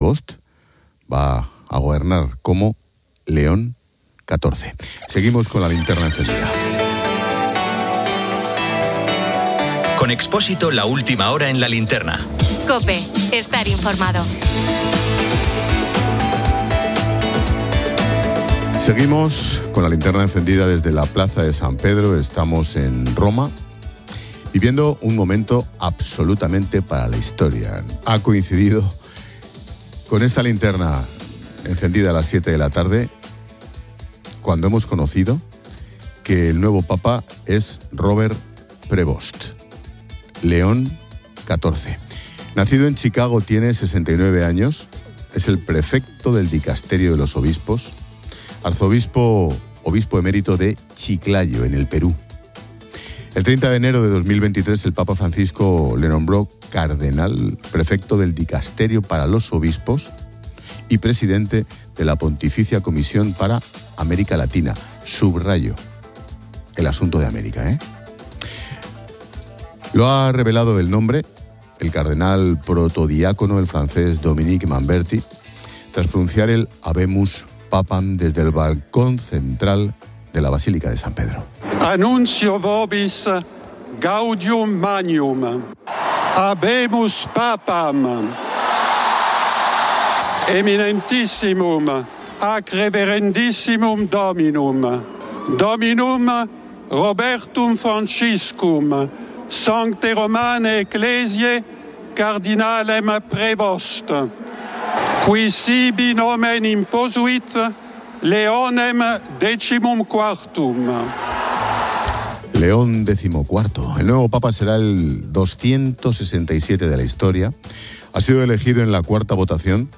Seguimos con la linterna encendida desde la Plaza de San Pedro.